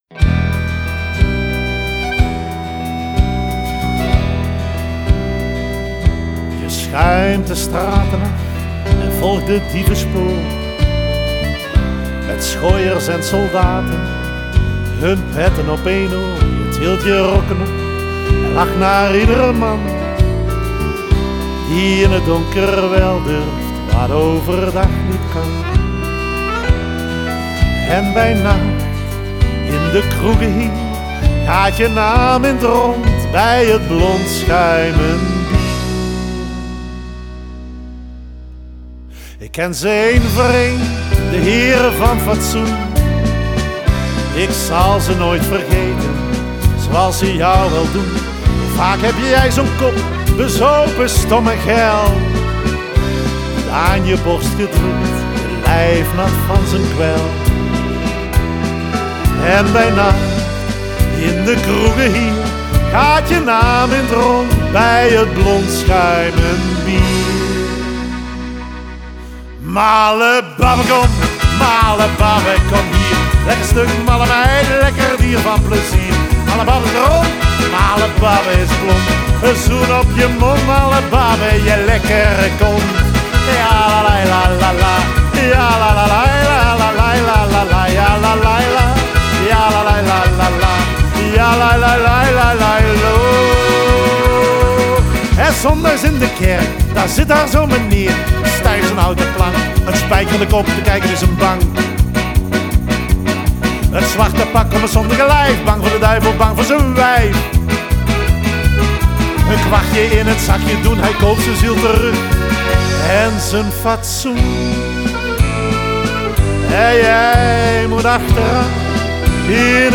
Они поют на северном нижне-франкском диалекте.
Genre: Ballad, folk